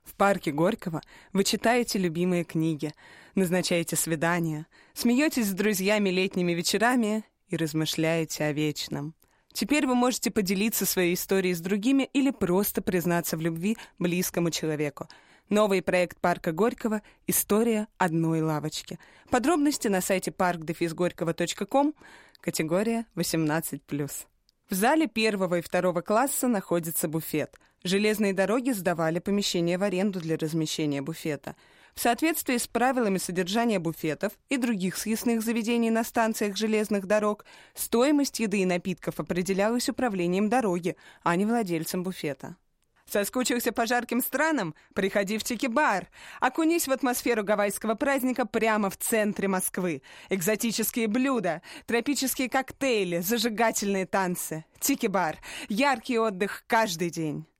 актёрская визитка
Шоурил